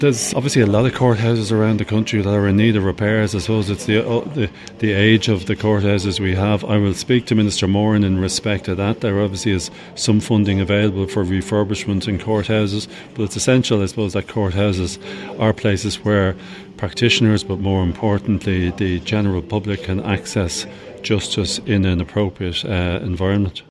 Speaking to Highland Radio News, Mr O’Callaghan said there are many courthouses across the country in need of attention, and he’ll discuss the Donegal situation with OPW Minister Kevin ‘Boxer’ Moran………….